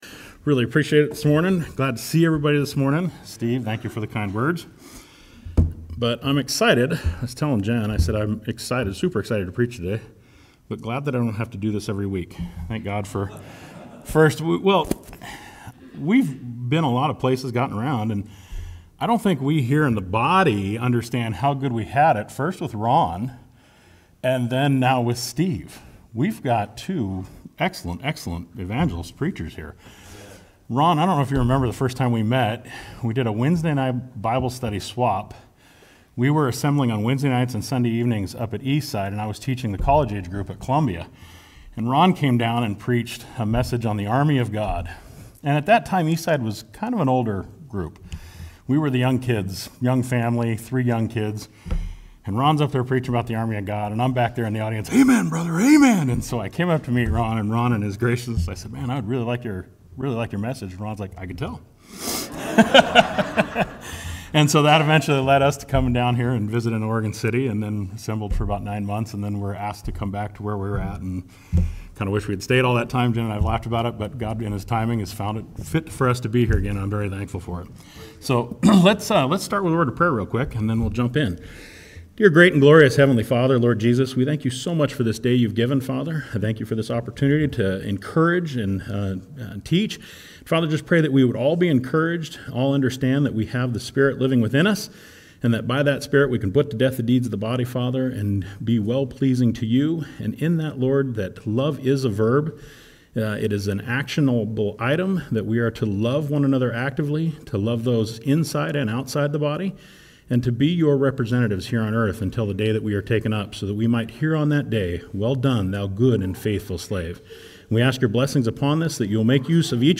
Sermons | Oregon City Church of Christ